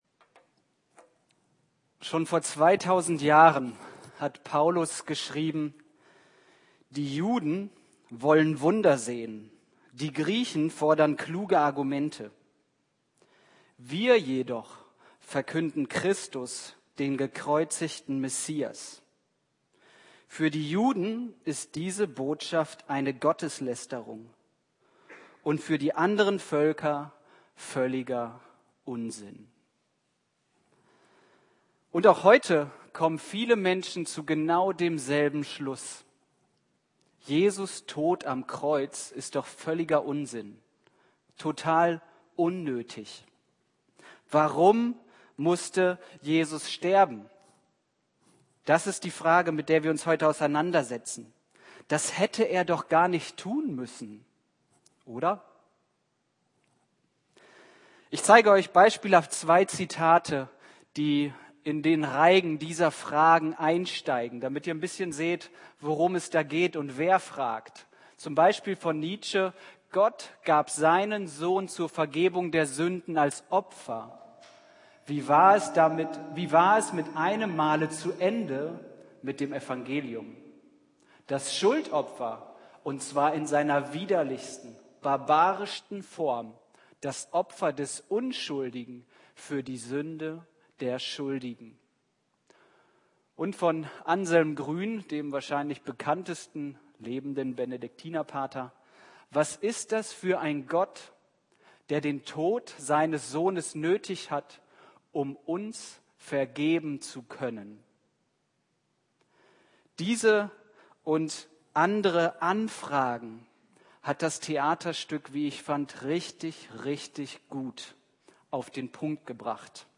Predigt zum Karfreitag